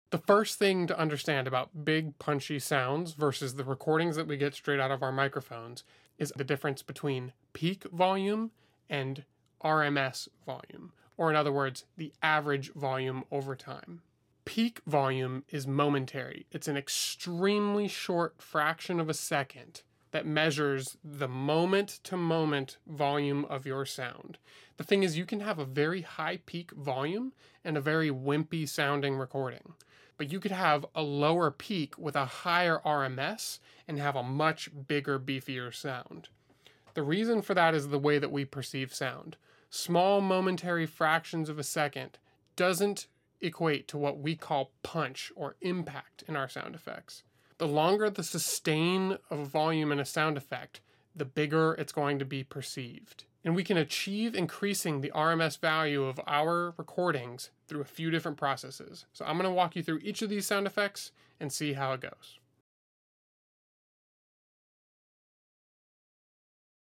💥 In this video you'll be taken through the process of punching up your sound effects from something thin and wimpy to something punchy and powerful. Learn step by step what it takes to go from an average recording to a Hollywood impact ⚡Distortion, Compression, Clipping, EQ, and more - its all covered in the full video on my YouTube Channel 🔊